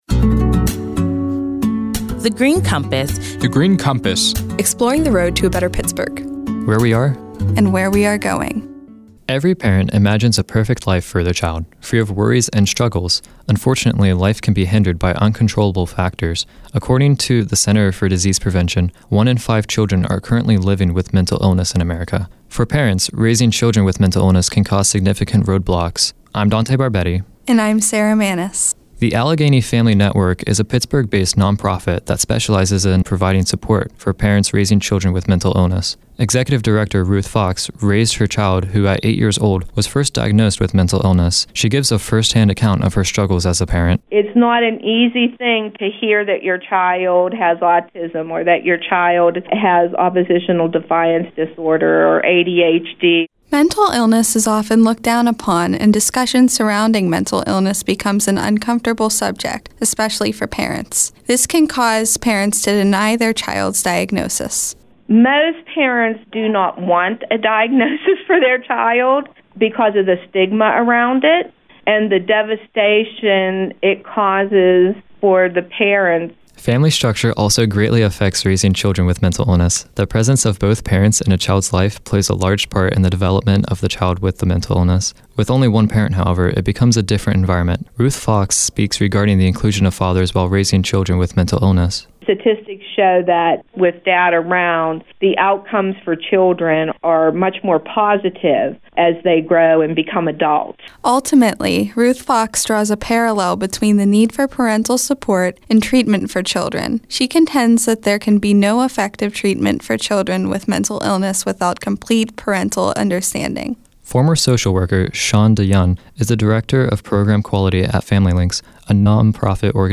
In July 2015, twenty-one recent high-school graduates created these radio features while serving as Summer Interns at The Heinz Endowments.